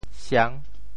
siang6.mp3